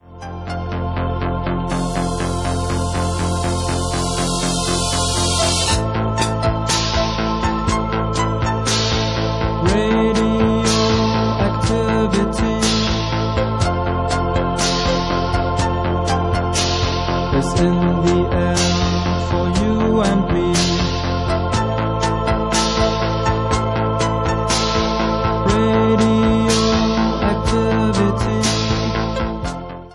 Anesthesia Ventilator